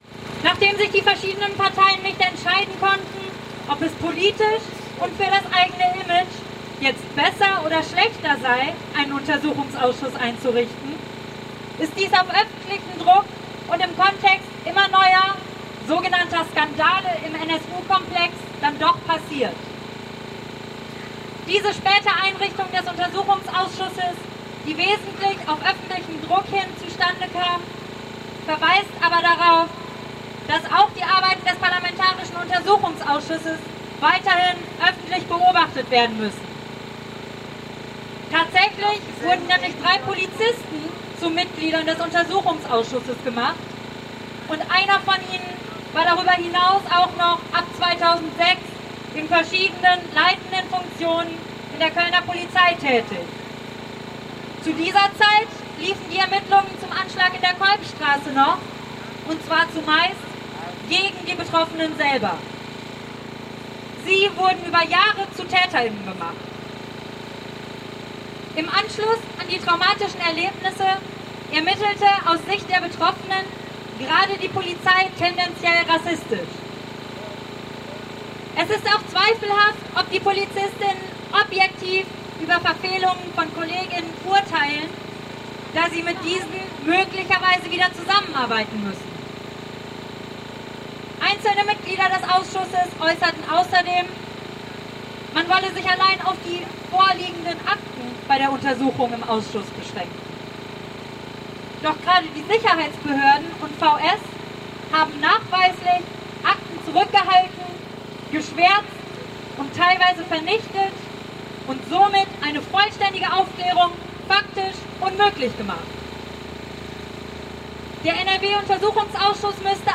Rede von [Name fehlt] auf der Abschlußkundgebung am Burgplatz
Ansprache von [Name fehlt] auf der Abschlußkundgebung am Burgplatz (Audio 8/8) [MP3]